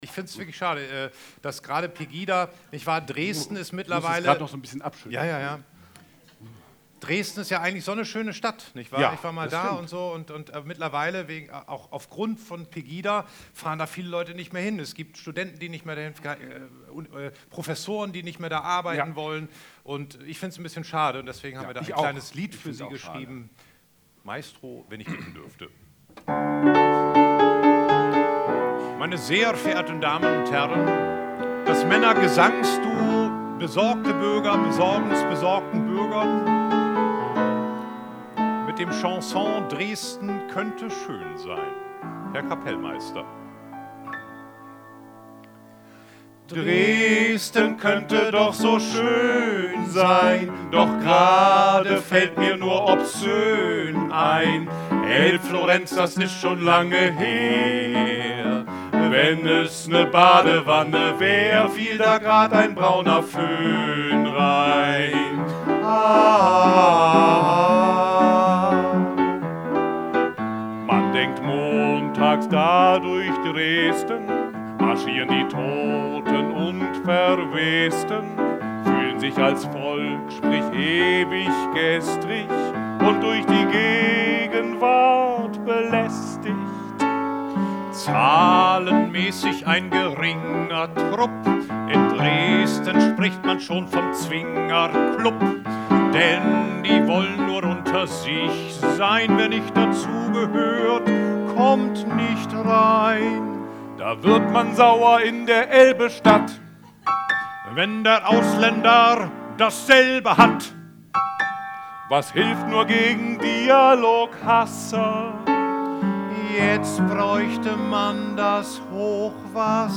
Events, Live-Übertragungen
BENEFIZ-COMEDY-MIXED-SHOW